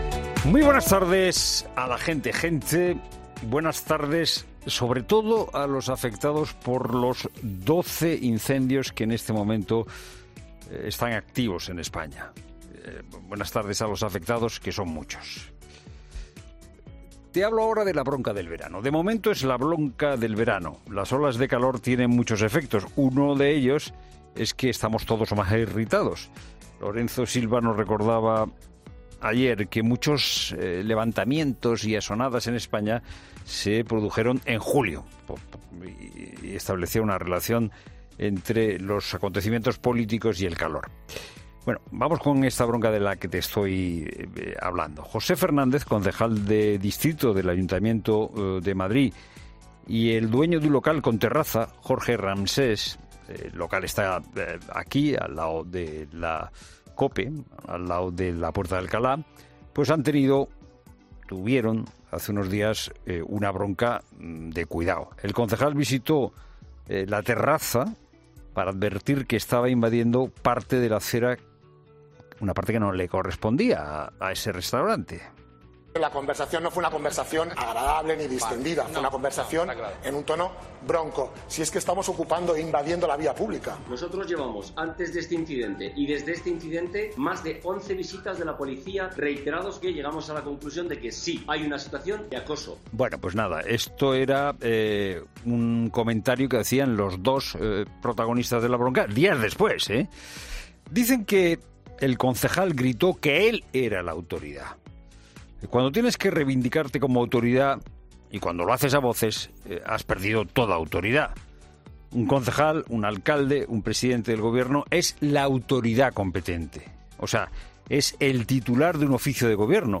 Monólogo de Fernando de Haro
El copresentador de 'La Tarde', Fernando de Haro, analiza las principales noticias de este miércoles